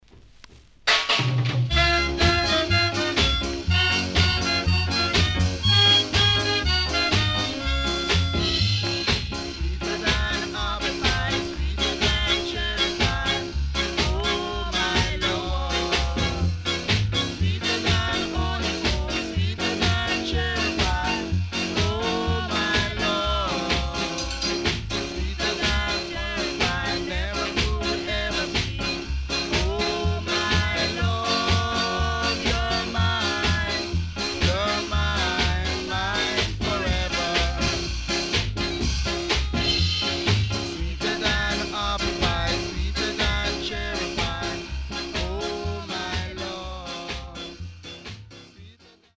HOME > SKA / ROCKSTEADY  >  SKA
SIDE A:少しチリノイズ入りますが良好です。